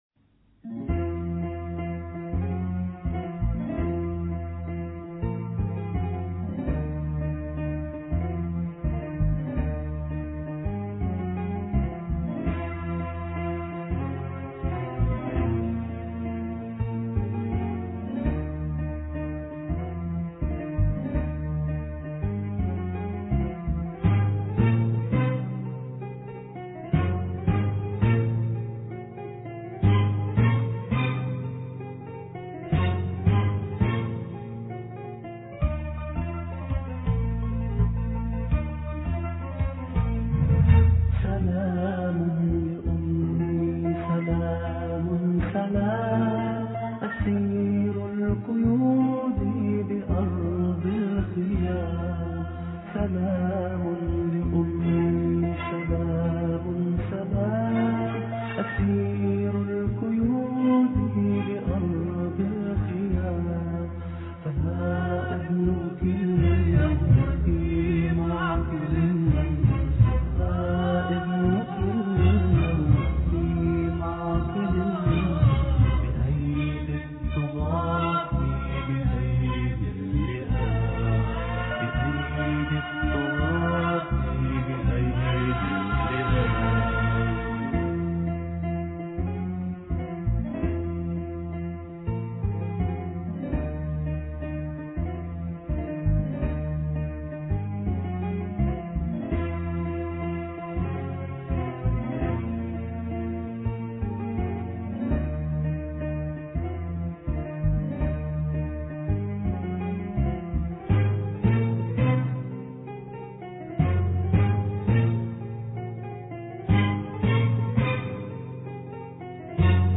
سلامي لأمي الإثنين 23 يونيو 2008 - 00:00 بتوقيت طهران تنزيل الحماسية شاركوا هذا الخبر مع أصدقائكم ذات صلة الاقصى شد الرحلة أيها السائل عني من أنا..